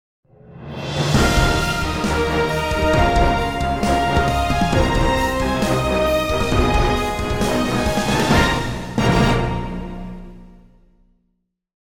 あ、いまあなたスターゲットしましたね？ 的なジングルです。